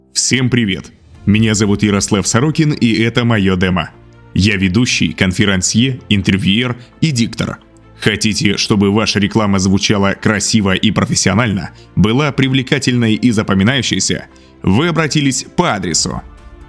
Пример звучания голоса
Муж, Другая/Средний